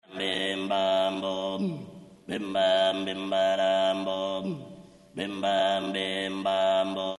This "coro" form of chanting requires a group of four male voices, referred to as "Oche", "Bassu ","Contra", and "Mesu Oche".
The timber is tenor or baritone for the "Oche"; guttural and in the throat (ingolato) for the "Bassu" (base), il "Contra", is a hollow and blurry voice (sfocato); and the "Mesu Oche", is the falsetto.
Examples of Tenores' Chants
bassu.mp3